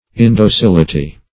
Indocility \In`do*cil"i*ty\, n. [L. indocilitas: cf. F.